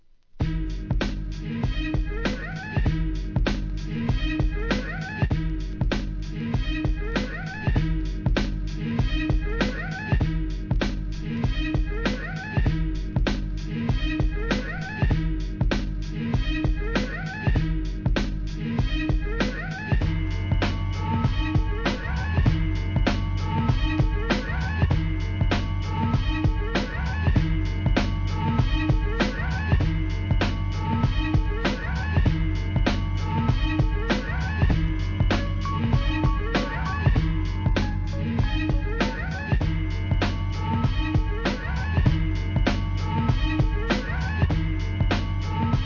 ブレイクビーツ、ブレンド、PARTY BREAKS!!